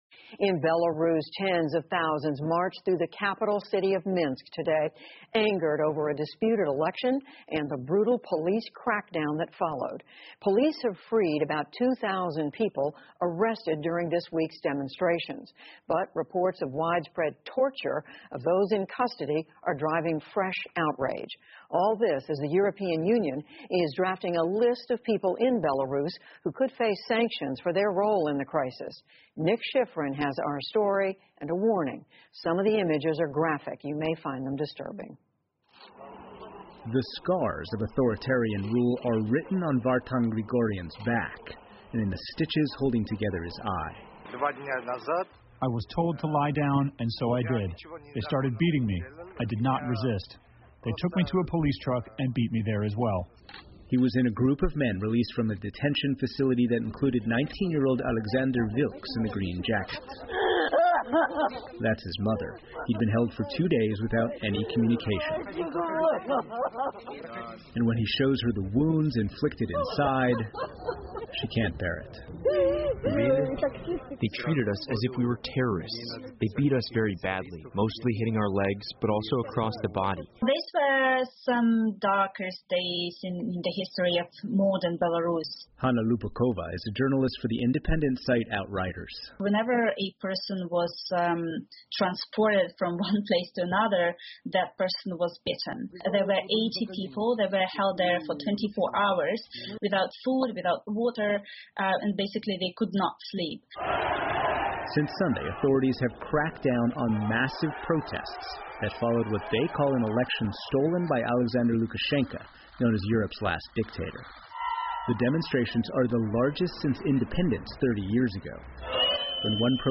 在线英语听力室PBS高端访谈:白俄罗斯政府的暴行的听力文件下载,本节目提供PBS高端访谈社会系列相关资料,内容包括访谈音频和文本字幕。